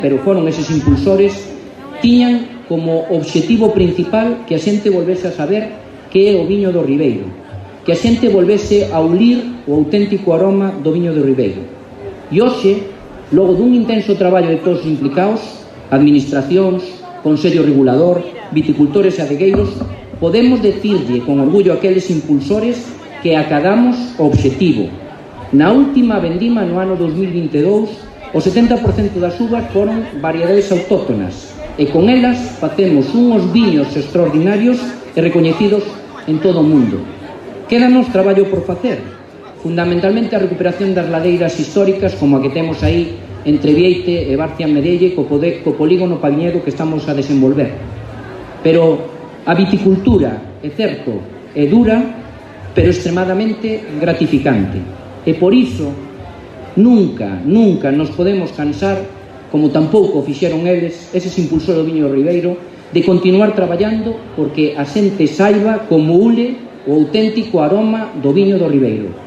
El titular de Medio Rural ejerció como pregonero de la XXXII Fiesta de la Vendimia de Leiro, donde ensalzó los vinos del Ribeiro en particular y toda la riqueza vitivinícola de Galicia en general.